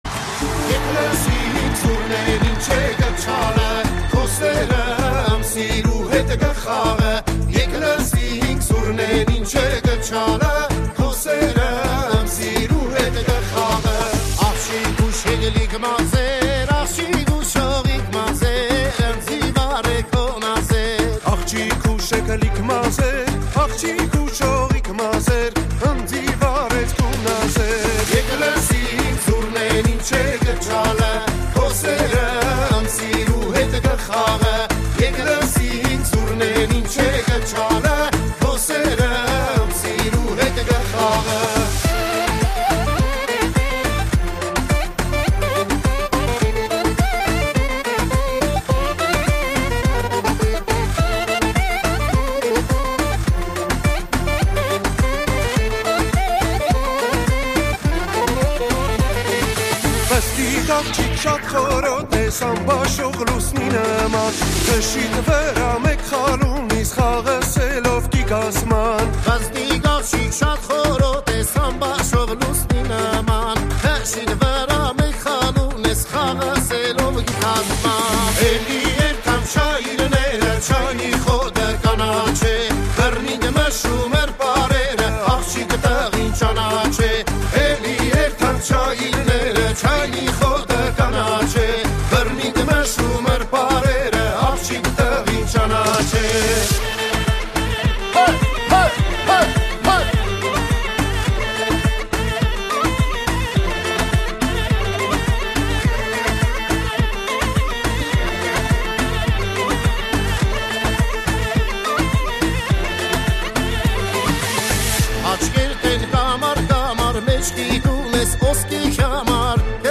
Erger 2026 / Joxovrdakan Azgagrakan Вчера, 23:25 17